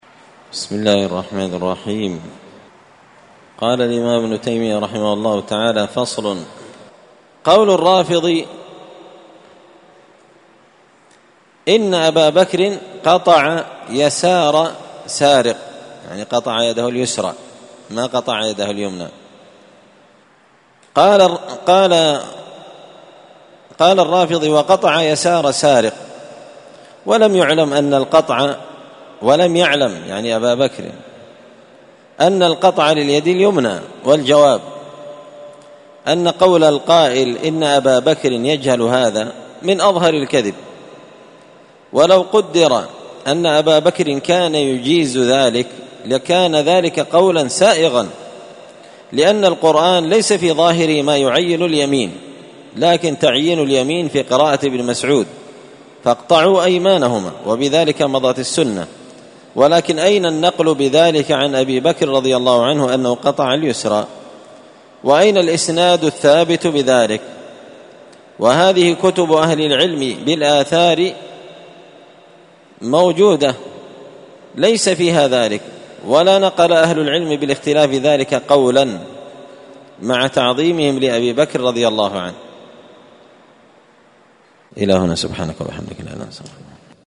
الأربعاء 25 ذو القعدة 1444 هــــ | الدروس، دروس الردود، مختصر منهاج السنة النبوية لشيخ الإسلام ابن تيمية | شارك بتعليقك | 18 المشاهدات
مسجد الفرقان قشن_المهرة_اليمن